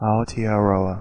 Ääntäminen
IPA : [ˈaoteaˌɾoa] IPA : /ˈaʊtɛəˌrəʊə/